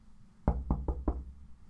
拟声词 " 敲击 1